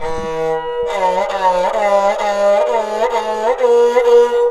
SARANGHI2 -R.wav